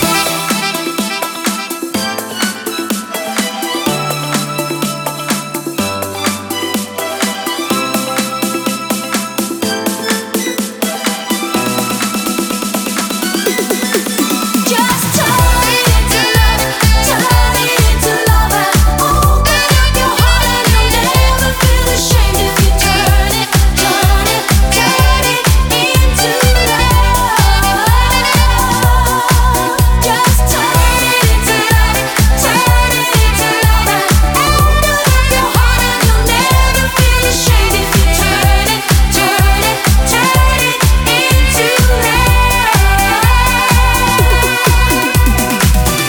Disco Remix